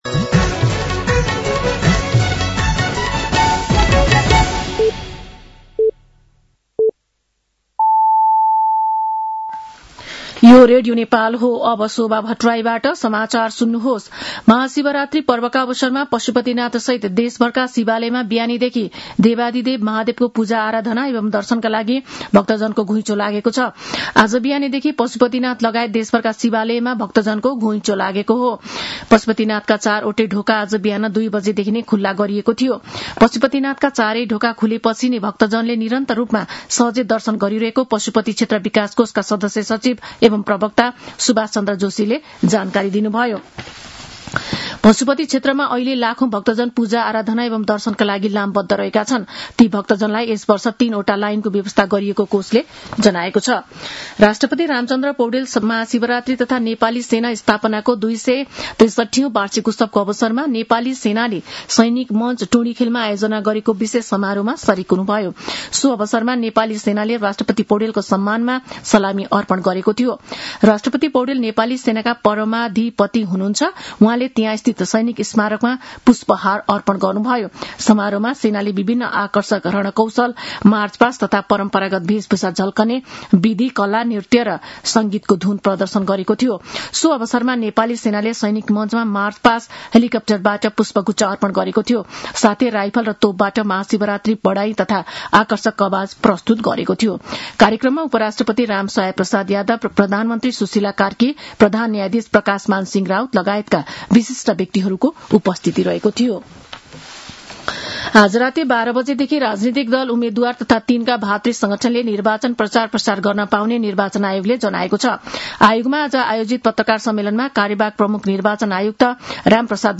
An online outlet of Nepal's national radio broadcaster
साँझ ५ बजेको नेपाली समाचार : ३ फागुन , २०८२
5.-pm-nepali-news-1-8.mp3